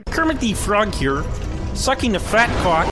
broteam kermit sound effect download for free mp3 soundboard online meme instant buttons online download for free mp3